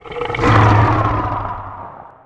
sound / monster2 / fire_dragon / attack3_1.wav
attack3_1.wav